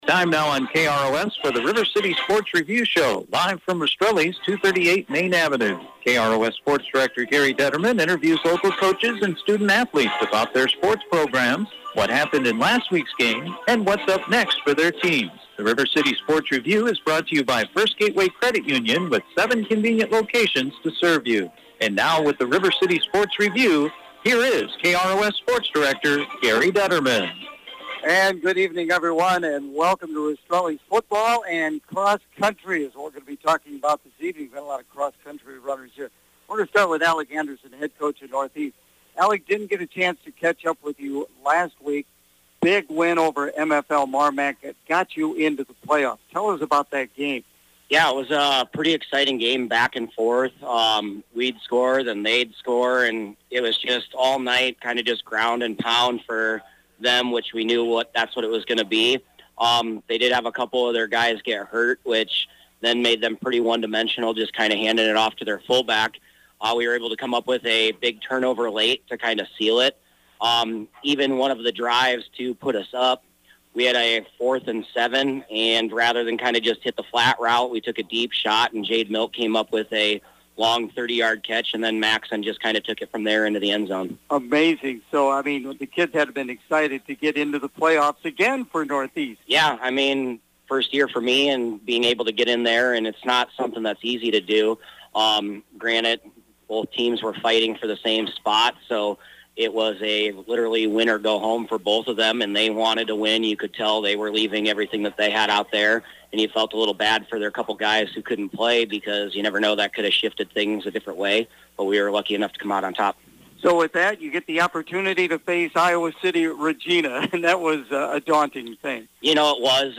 on Wednesday night from Rastrelli’s Restaurant
with the area coaches to preview this weekends sports action